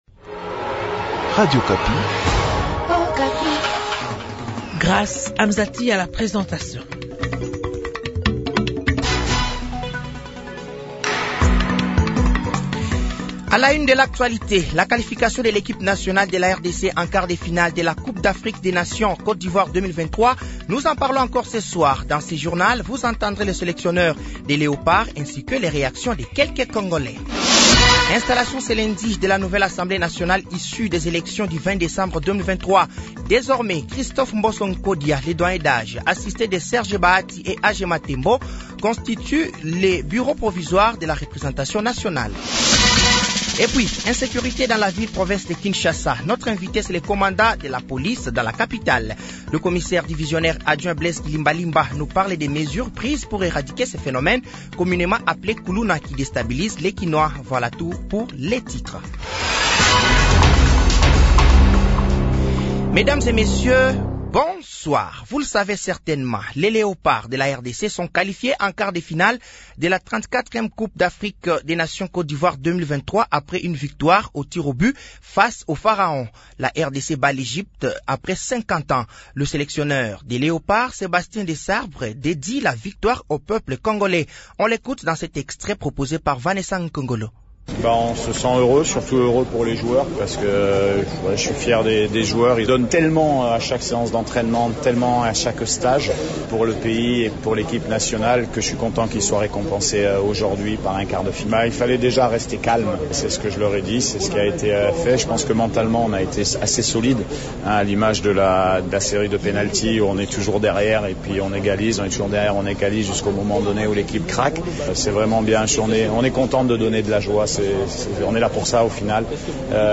Journal français de 18h de ce lundi 29 janvier 2024